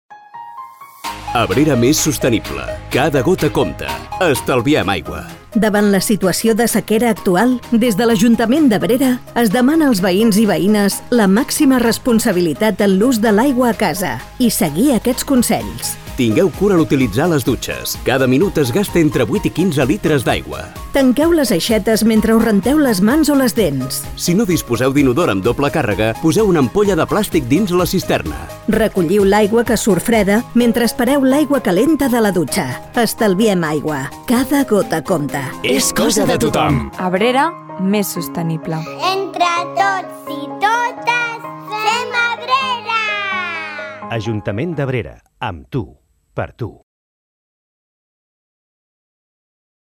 Escolta la falca de Ràdio Abrera i l'Ajuntament d'Abrera de mesures per a l'estalvi d'aigua